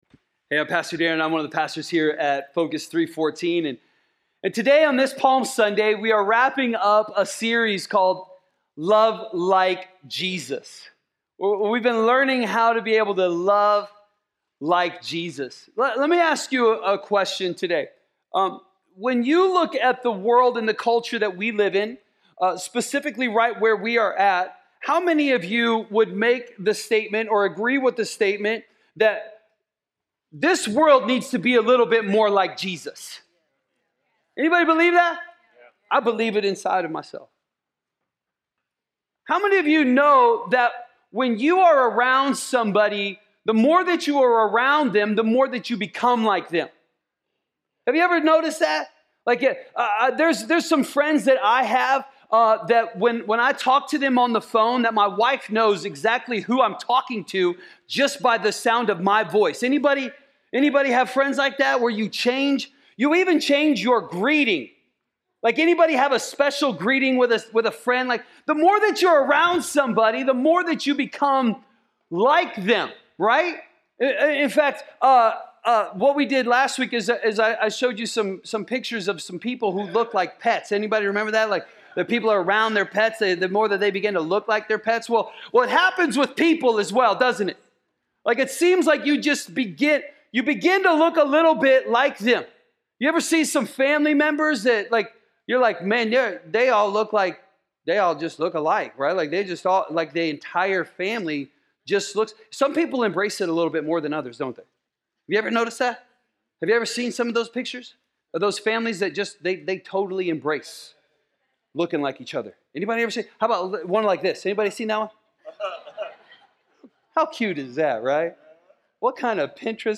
A message from the series "Love Like Jesus."